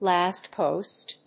音節làst póst